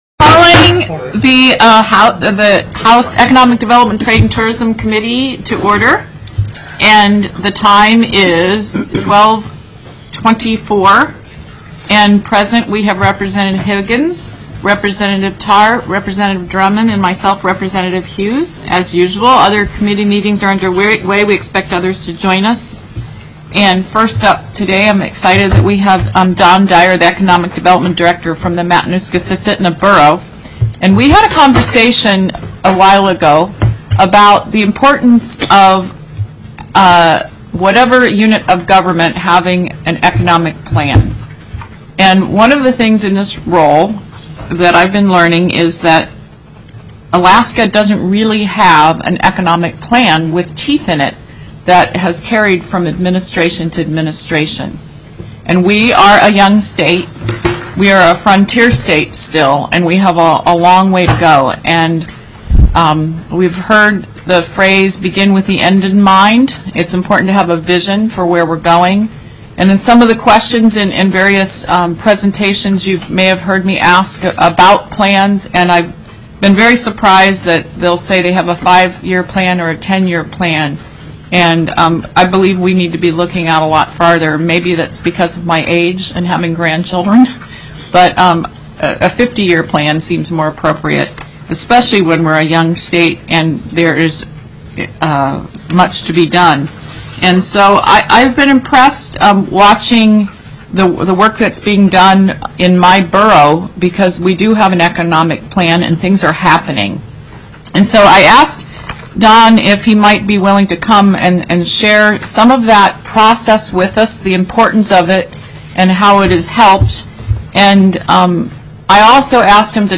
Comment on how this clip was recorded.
03/19/2013 11:15 AM House ECON. DEV., TRADE & TOURISM first hearing in first committee of referral + teleconferenced = bill was previously heard/scheduled